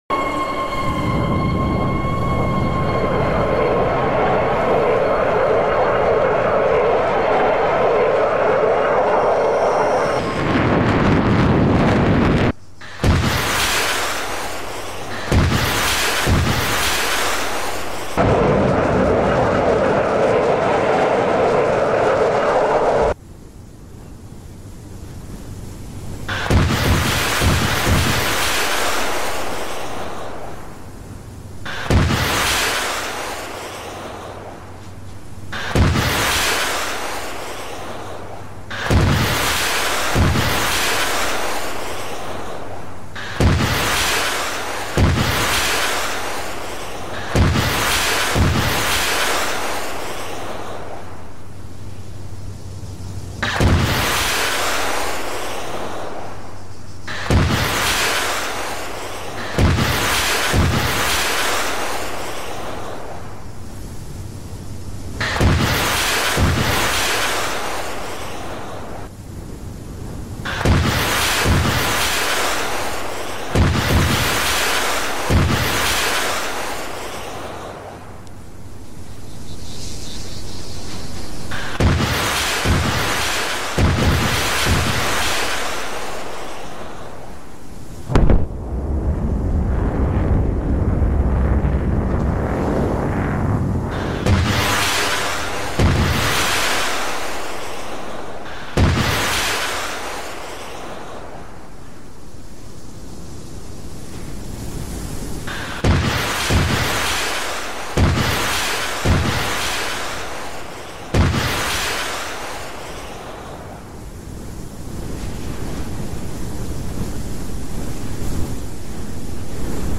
C RAM Air Defense System in sound effects free download